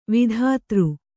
♪ vidhātř